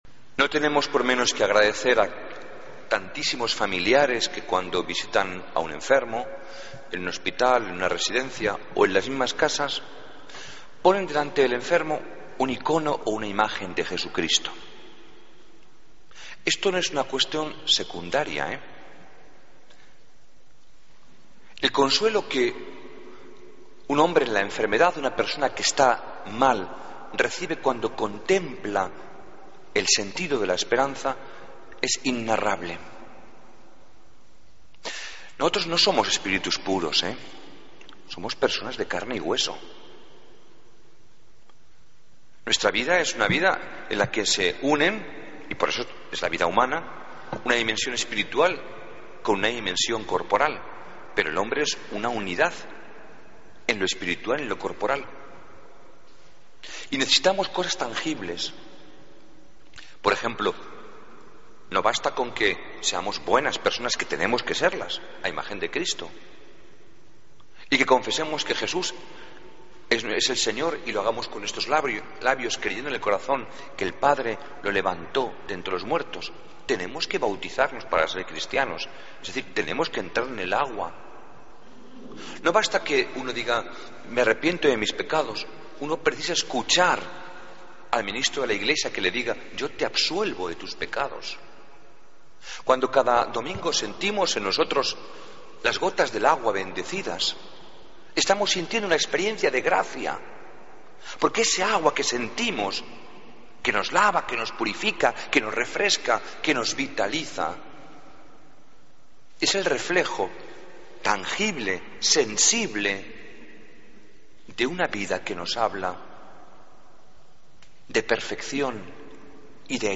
Homilía del domingo 18 de mayo de 2014